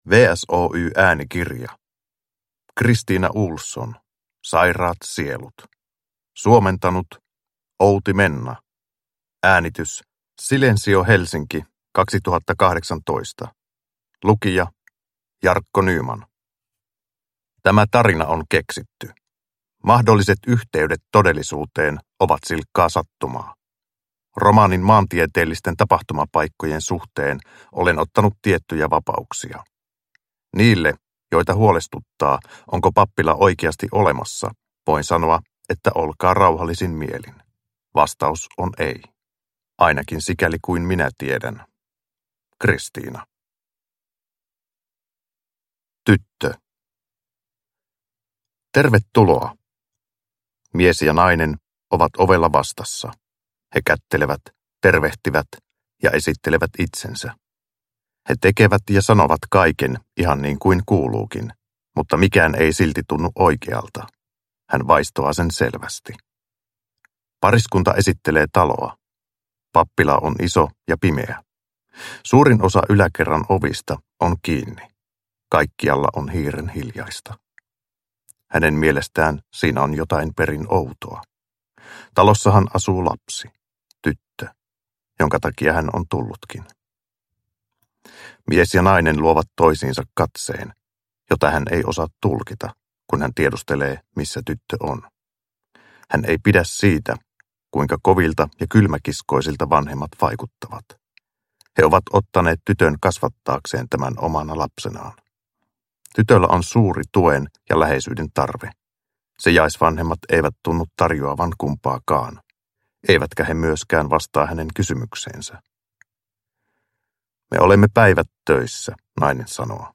Sairaat sielut – Ljudbok – Laddas ner